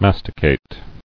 [mas·ti·cate]